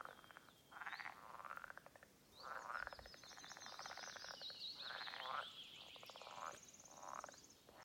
Amphibians -> Frogs ->
edible/pool frog Pelophylax sp., Pelophylax sp.